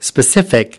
23 specific (adj) /spəˈsɪfɪk/ Cụ thể, rõ ràng, rành mạch